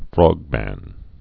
(frôgmăn, -mən, frŏg-)